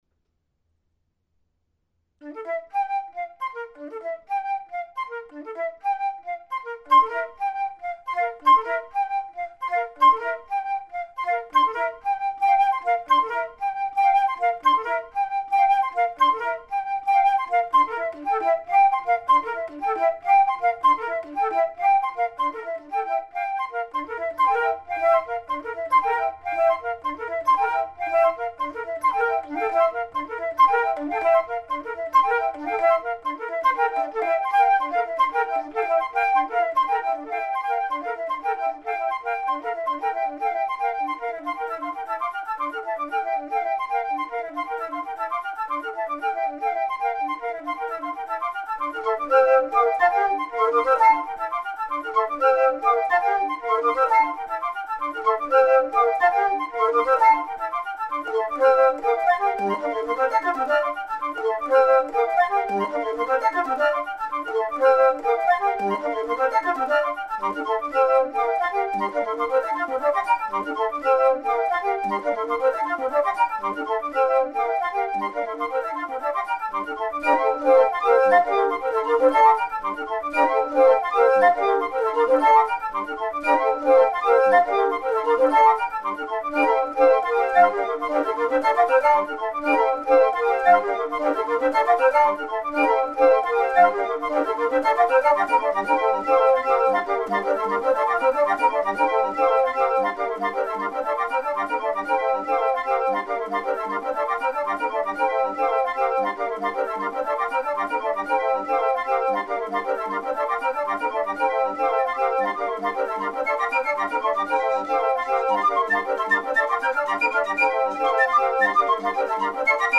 Minimal music for solo flute.